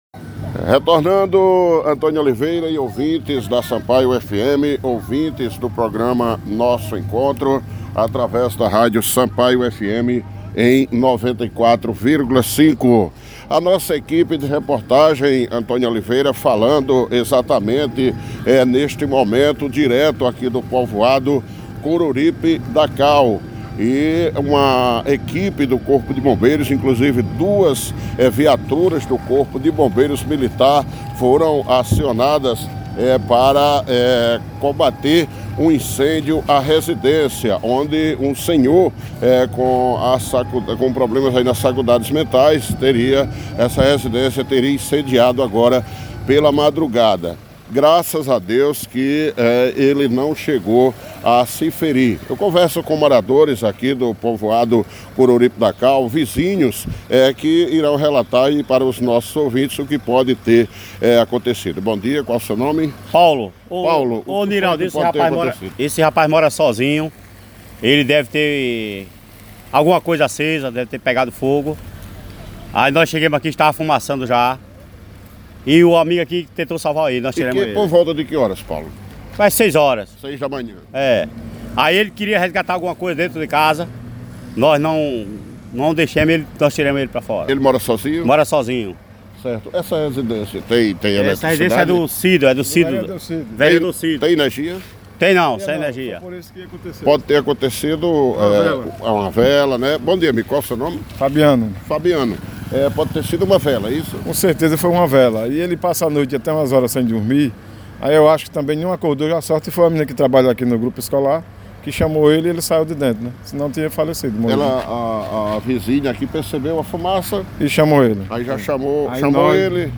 Entrevista-com-moradores-e-bombeiro.mp3